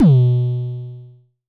Index of /m8-backup/M8/Samples/Drums/LookIMadeAThing Kicks - Vermona Kick Lancet/Distortion Kicks/Dist Kick - Precise
Dist Kicks 23 - B2.wav